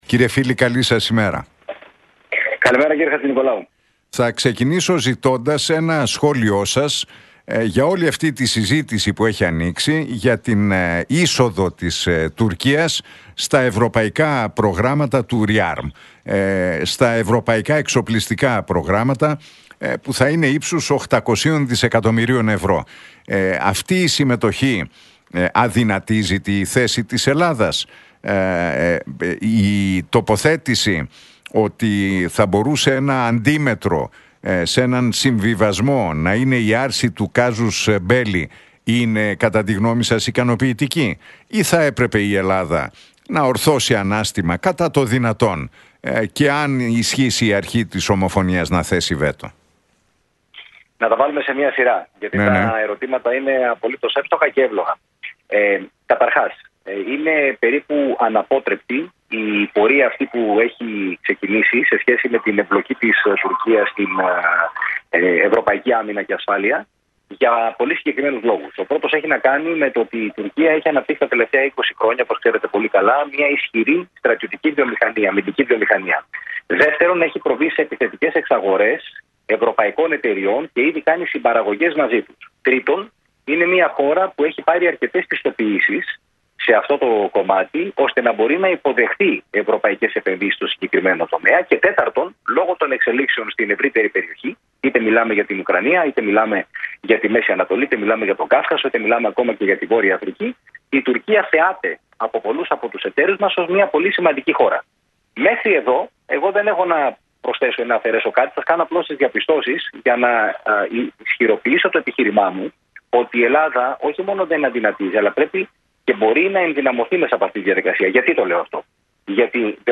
Για την ενδεχόμενη εμπλοκή της Τουρκίας στην άμυνα και ασφάλεια της Ευρωπαϊκής Ένωσης μίλησε ο διεθνολόγος, Κωνσταντίνος Φίλης στον Νίκο Χατζηνικολάου από την συχνότητα του Realfm 97,8.